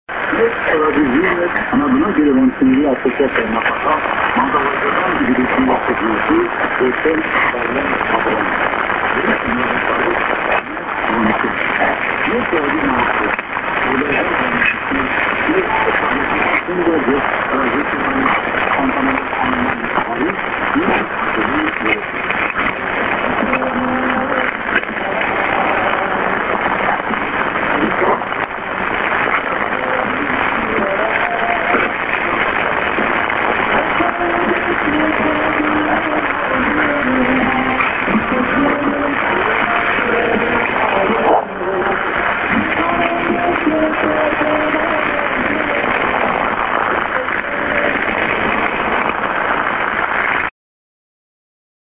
00/07/02 01:44 11,690　 105　 poor
end ANN(man)??-> s/off